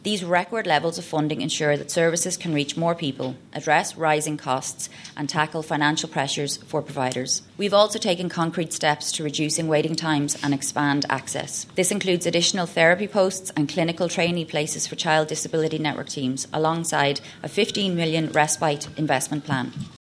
Donegal Election Candidate Senator Nikki Bradley, says Fine Gael will improve the lives of disabled people……………..